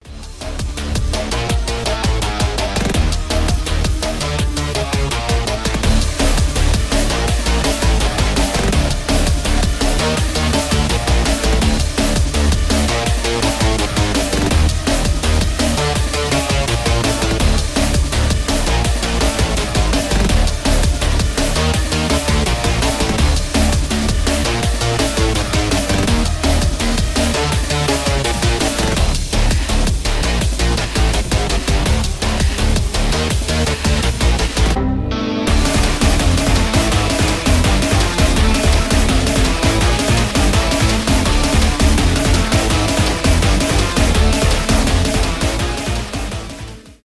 без слов , электронные